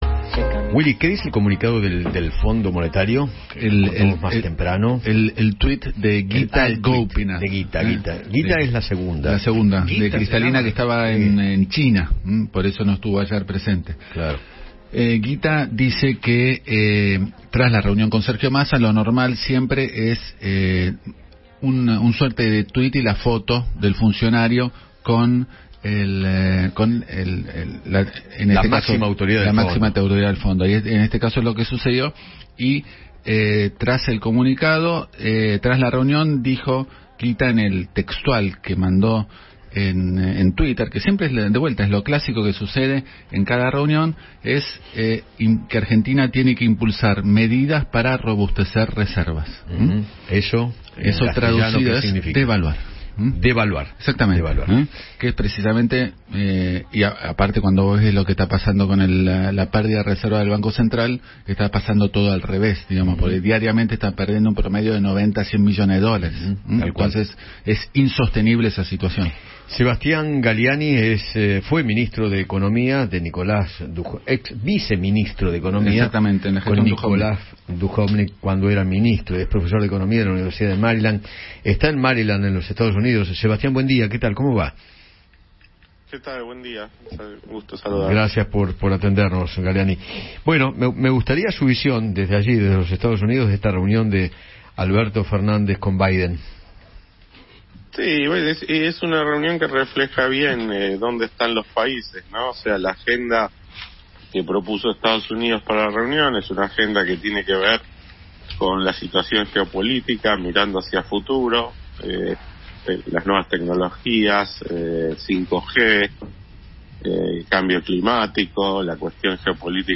Sebastián Galiani, viceministro de Nicolás Dujovne en Economía, dialogó con Eduardo Feinmann sobre el encuentro de ayer entre Sergio Massa y Gita Gopinath, la segunda del FMI.